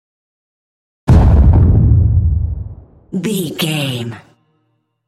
Dramatic hit deep trailer
Sound Effects
Atonal
heavy
intense
dark
aggressive